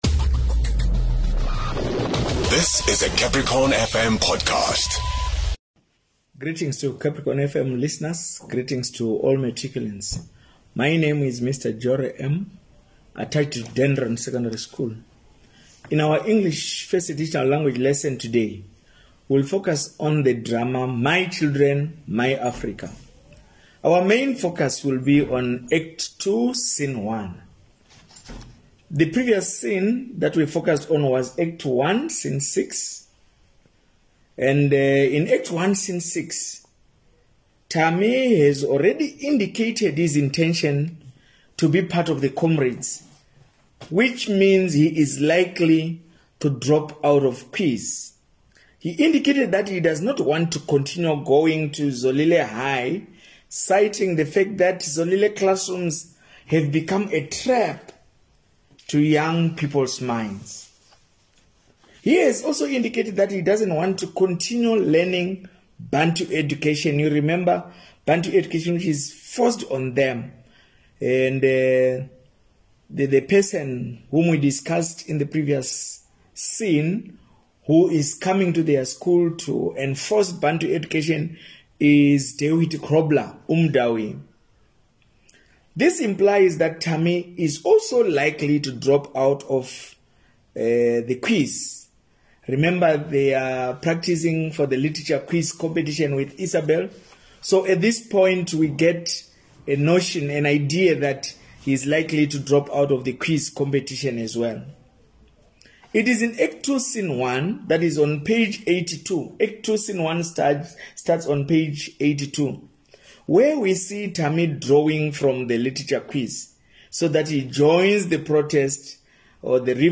RADIO LESSONS